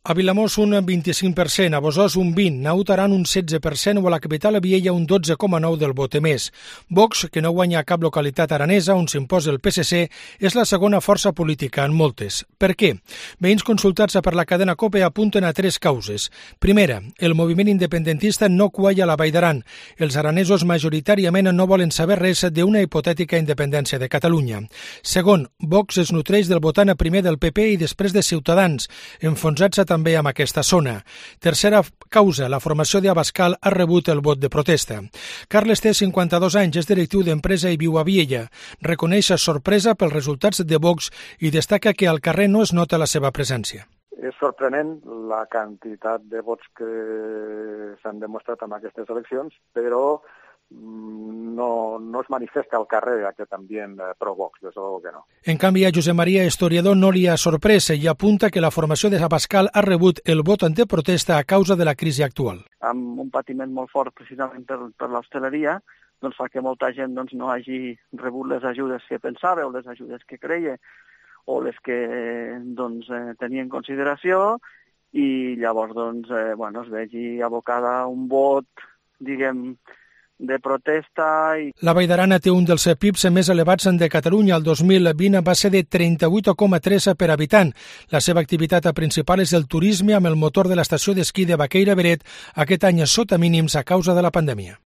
Crónica de Cope Cataluña para explicar los motivos de la pujanza de Vox en el Valle de Arán